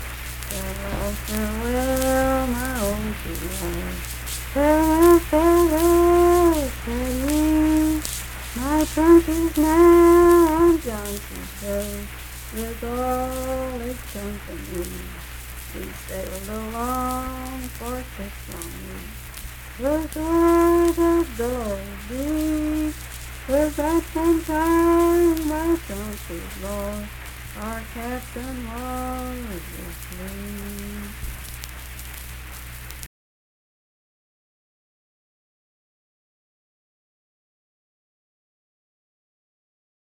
Johnson's Boat - West Virginia Folk Music | WVU Libraries
Unaccompanied vocal music
Verse-refrain 2(4).
Voice (sung)
Braxton County (W. Va.), Sutton (W. Va.)